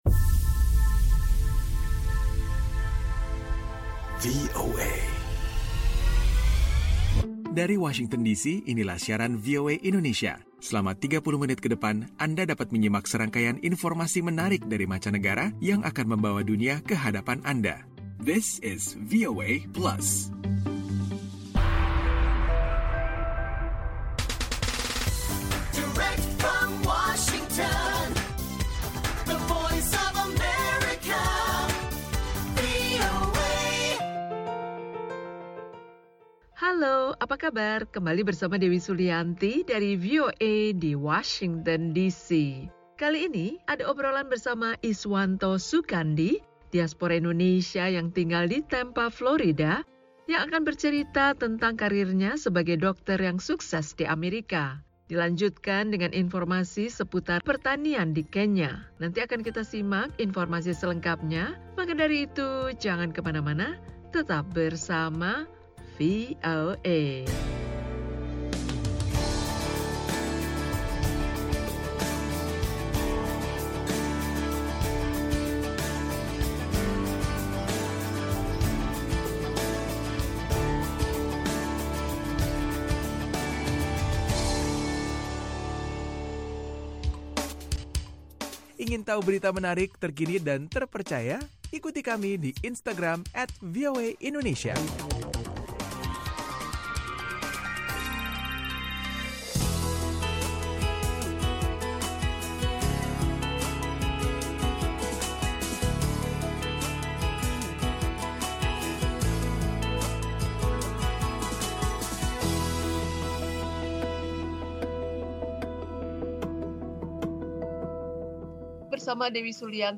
VOA Plus kali ini akan menyajikan obrolan bersama seorang diaspora Indonesia yang berprofesi sebagai dokter seputar perjalanan karirnya menjadi seorang dokter ahli bedah yang sukses. Ada pula informasi tentang perubahan iklim yang berdampak kekeringan pada lahan petani di Kenya.